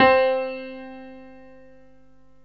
PIANO 0014.wav